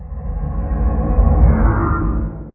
elder_idle3.ogg